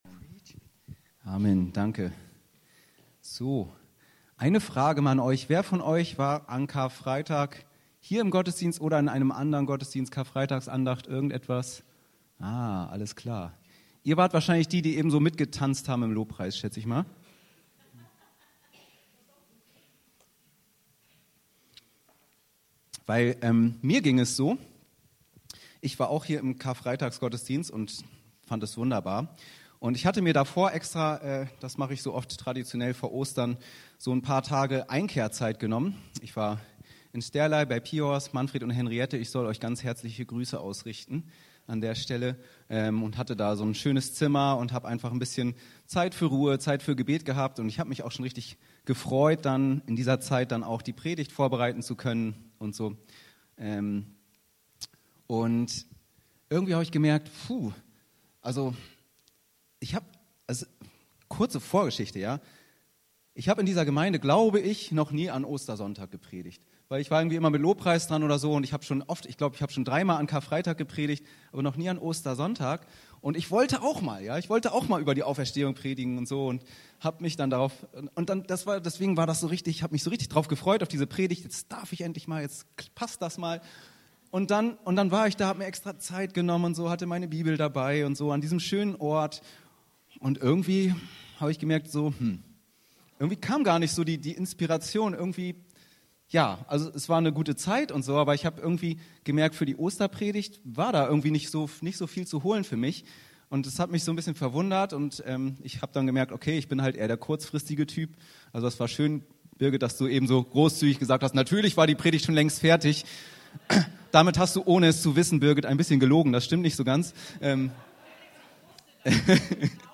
- Predigt zu Ostern